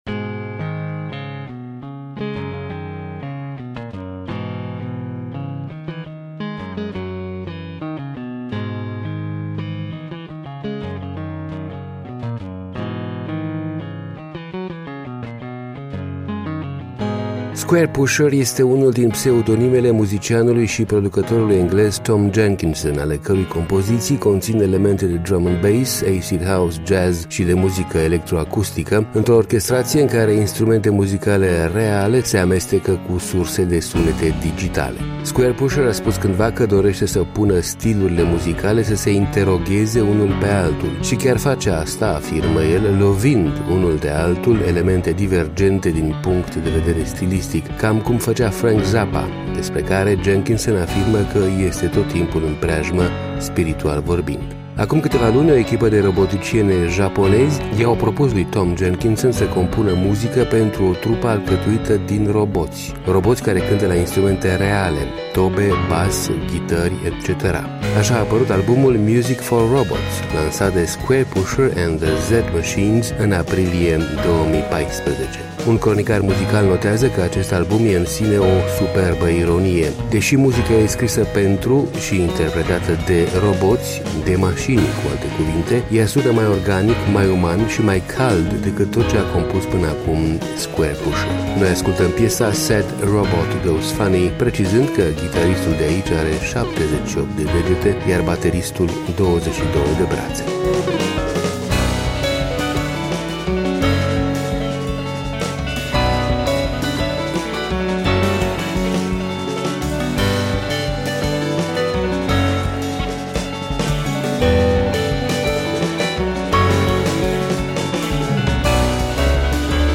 Ghitaristul are 78 de degete, bateristul 22 de brațe.
ea sună mai organic, mai uman și mai cald